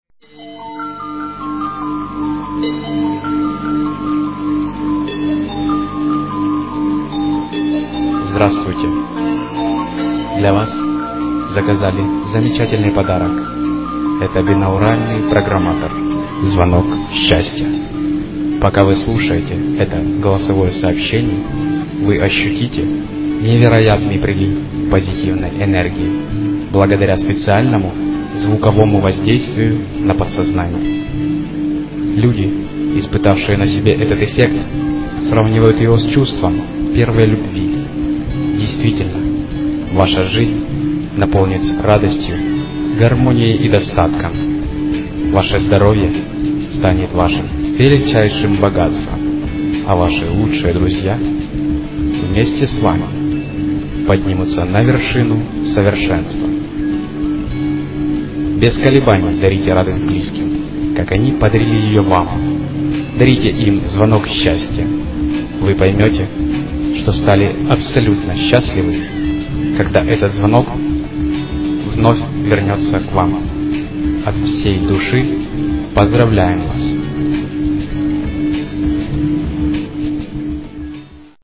Прикольные голосовые поздравления - это пародии, шутки, розыгрыши, забавные позитивные песни и музыкальные открытки.